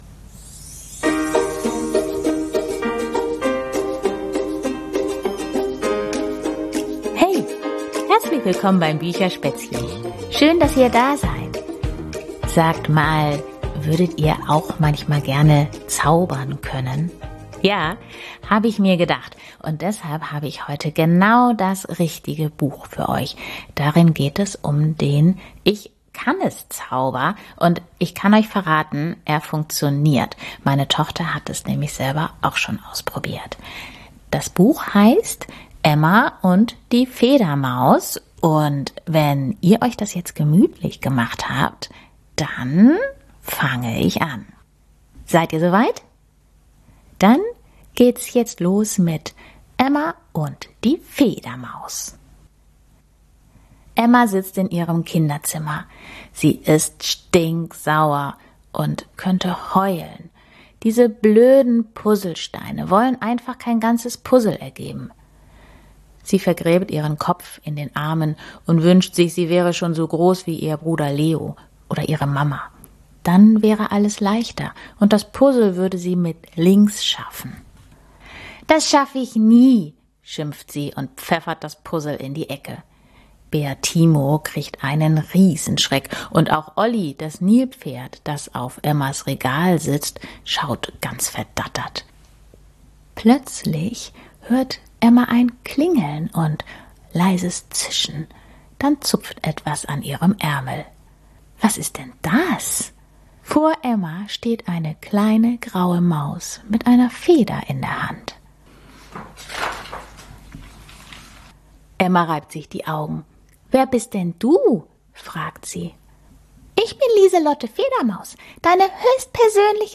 #114 – Vorgelesen: "Emma und die Federmaus"